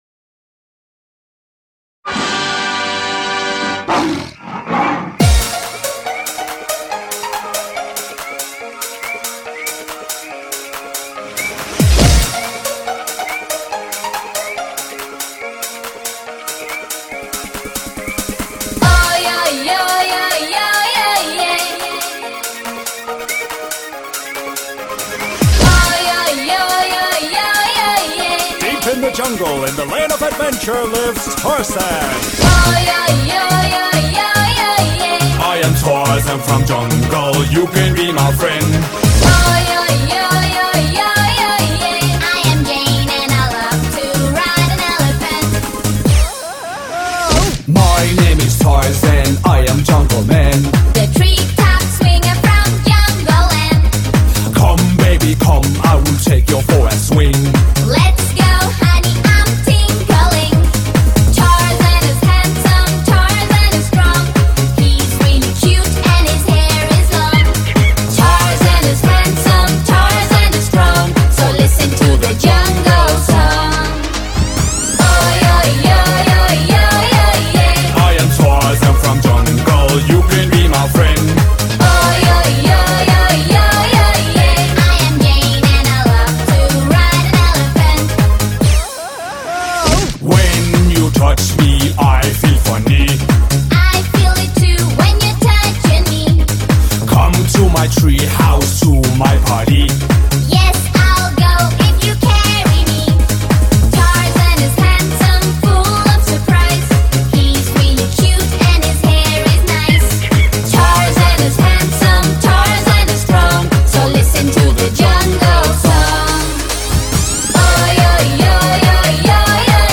Вот развеселая детская песенка.